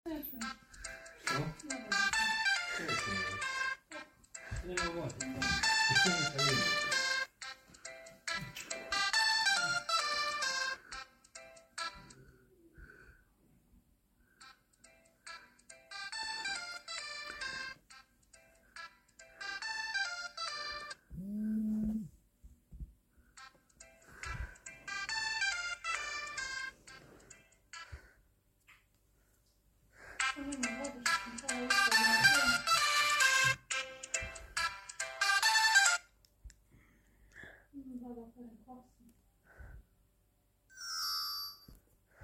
Myki watch ringtone sound effects free download